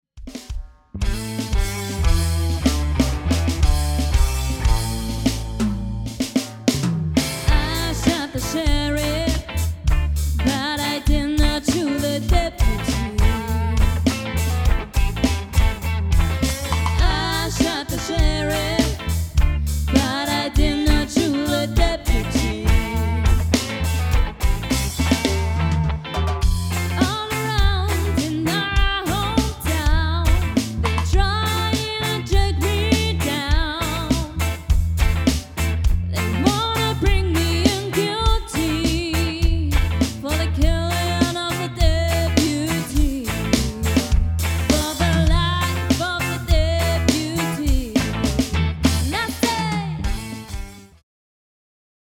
Demosounds
Cover
Gesang
Gitarre
Bass
Schlagzeug